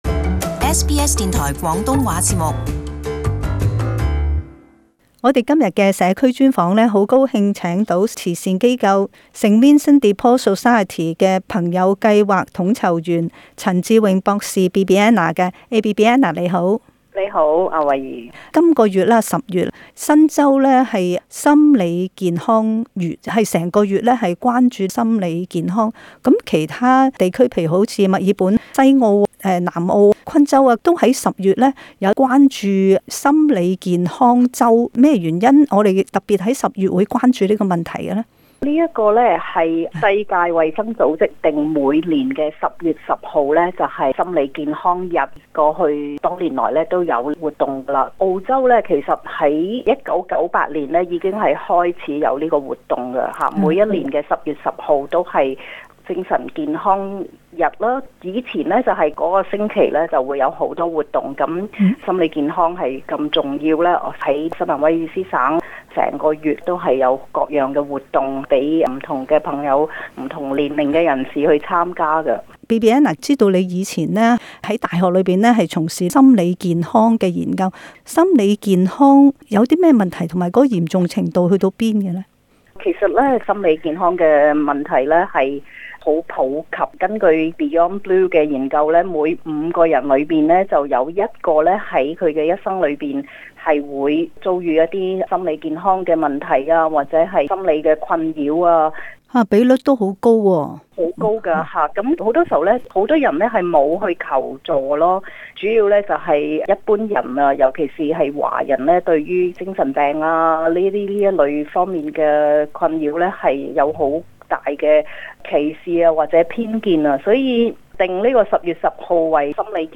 【社區專訪】世界精神健康日